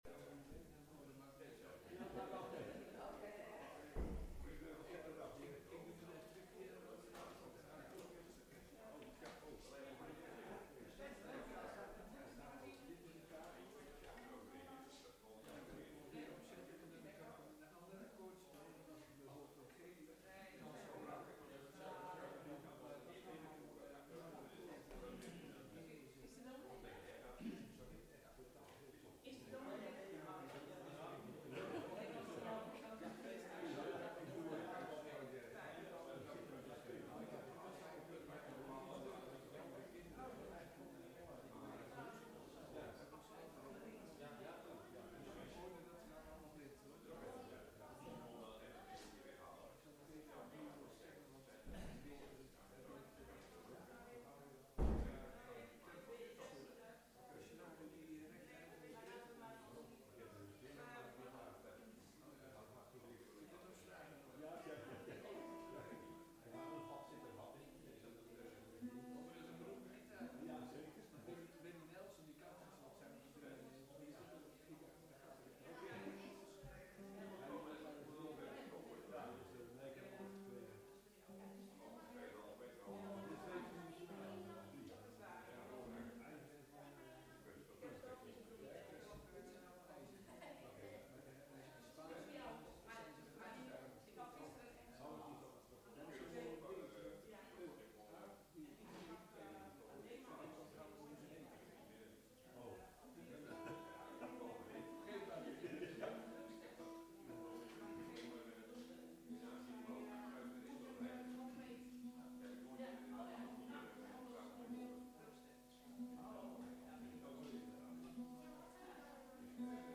Diensten beluisteren
Elke zondag om 10.00 uur komt de gemeente samen in een kerkdienst.
Tijdens de samenkomsten is er veel aandacht voor muziek, maar ook voor het lezen van Gods woord en het overdenken hiervan. We zingen voornamelijk uit Opwekking en de Johannes de Heer bundel.